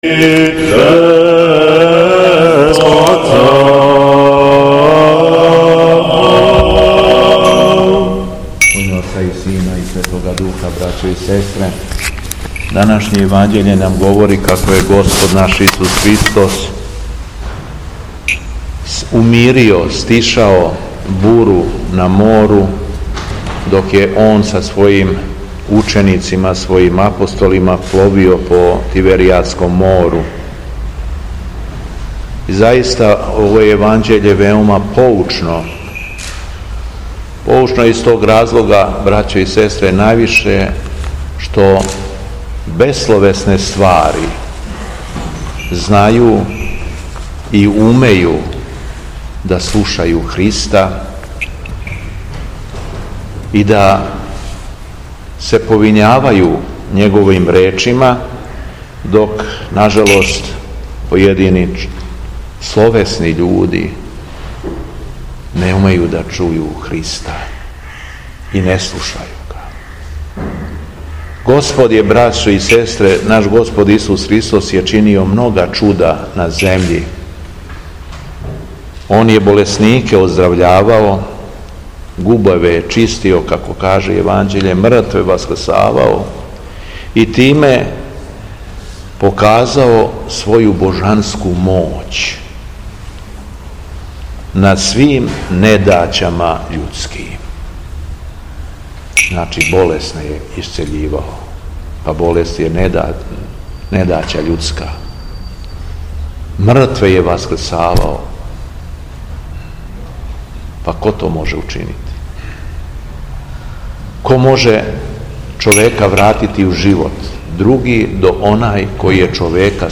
ДРУГИ ЧЕТВРТАК ПО ДУХОВИМА У СТАРОЈ ЦРКВИ У КРАГУЈЕВЦУ
Беседа Његовог Преосвештенства Епископа шумадијског г. Јована